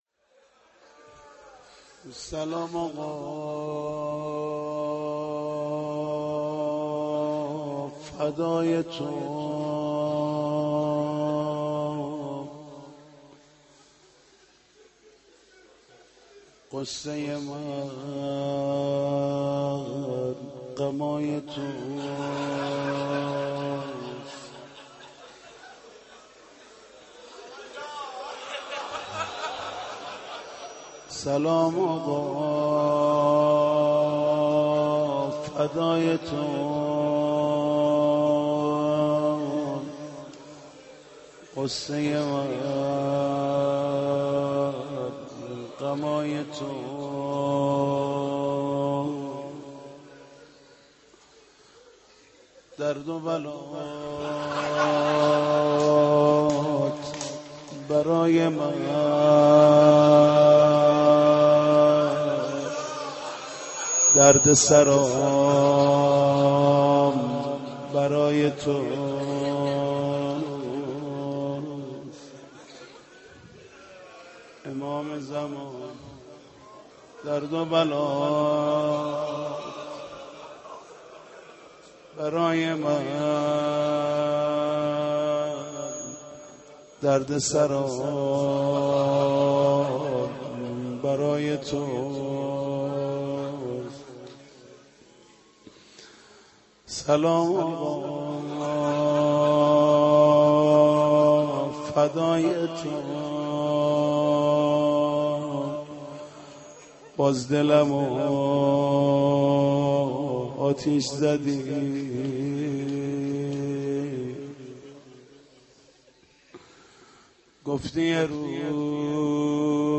روضه خوانی حاج محمود کریمی